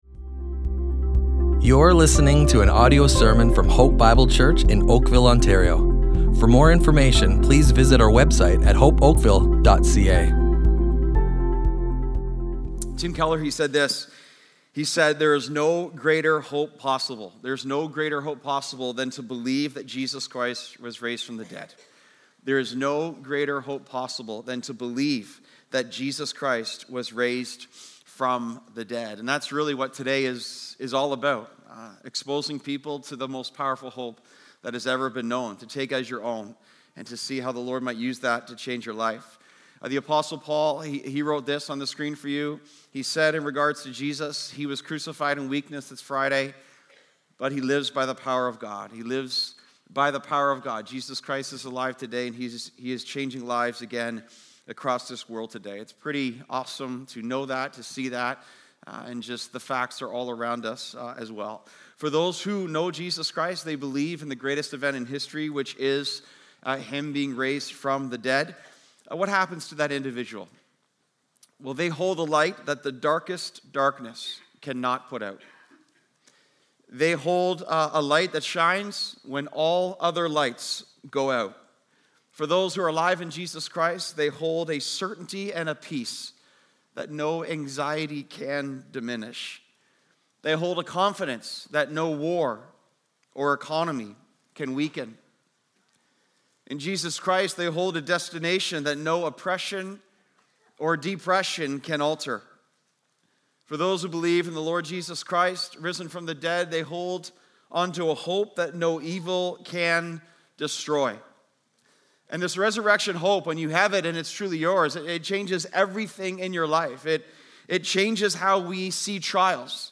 Hope Bible Church Oakville Audio Sermons Easter 2026 // Hope Resurrected!